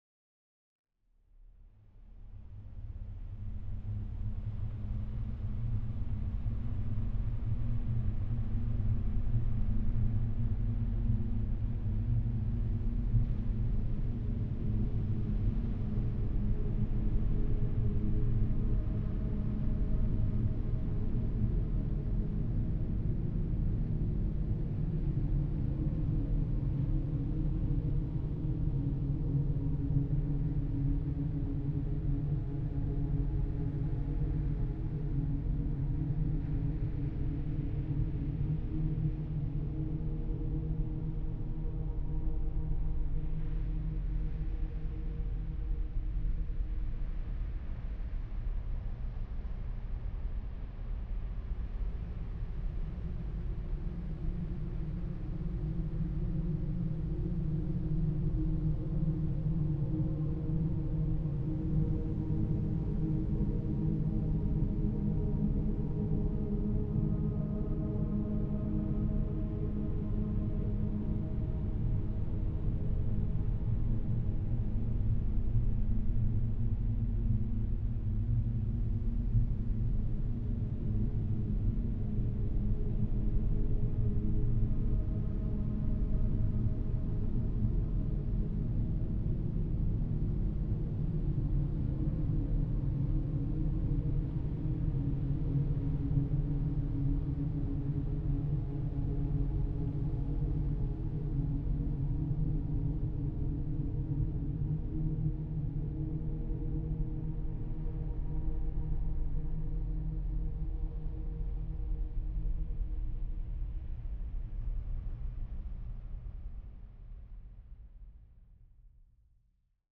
occasional ambient music releases